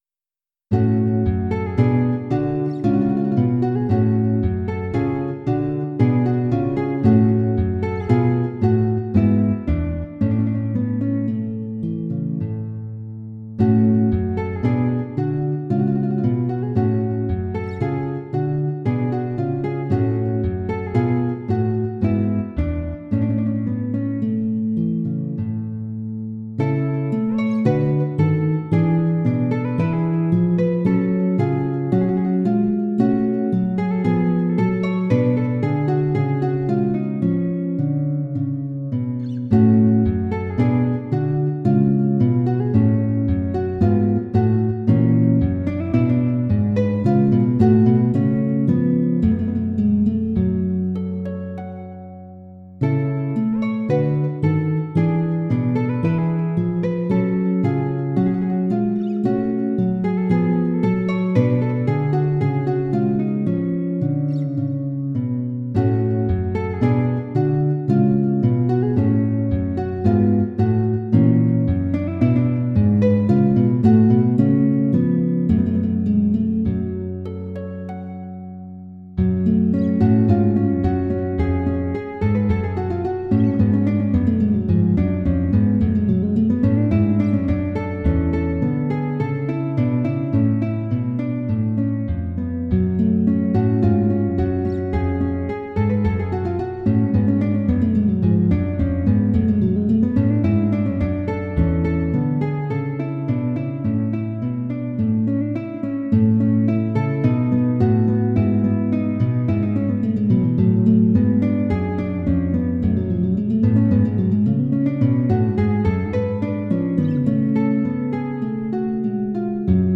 polonaise.mp3